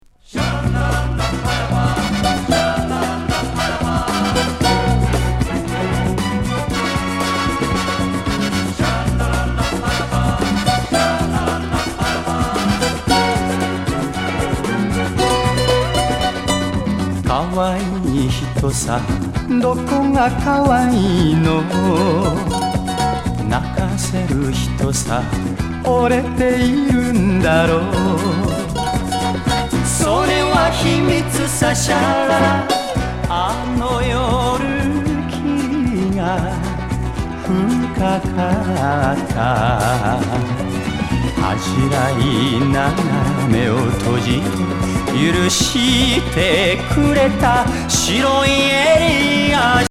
パーカッシブ・グルーヴィなシャランラランラ・ムードコーラス歌謡